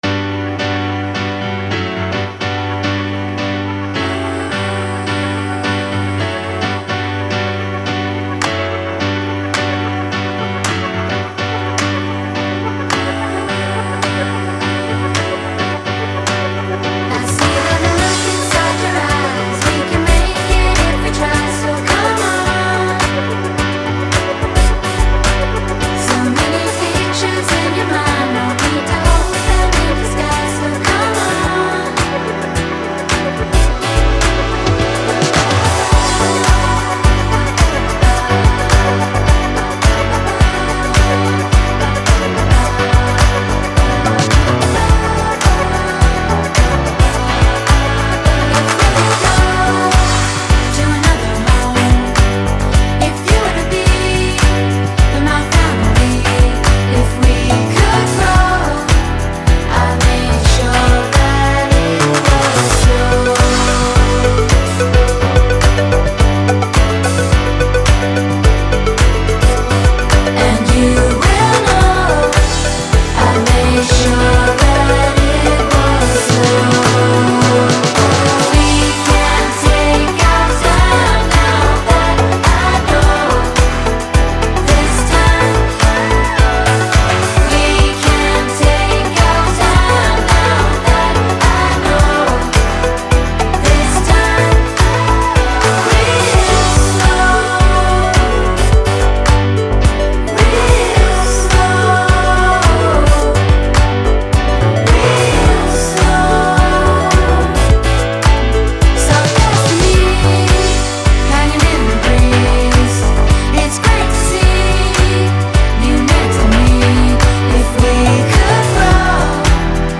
full-on disco and house-influenced dance band